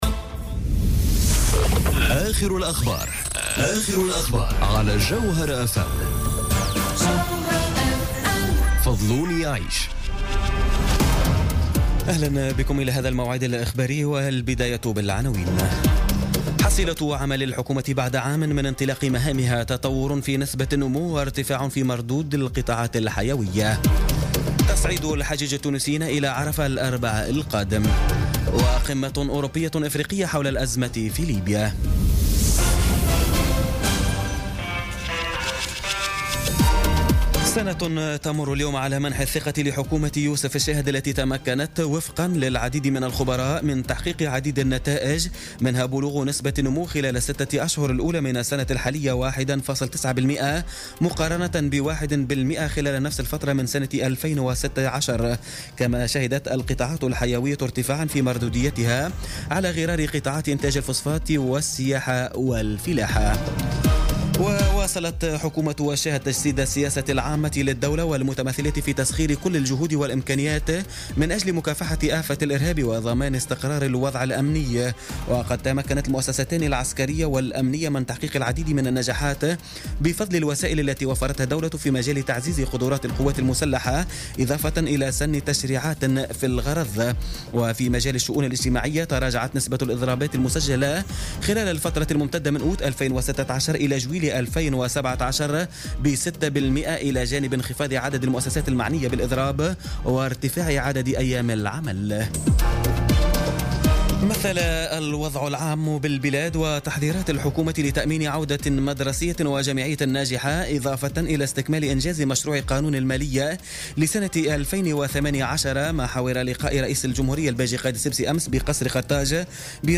نشرة أخبار منتصف الليل ليوم السبت 26 أوت 2017